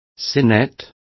Also find out how sellos is pronounced correctly.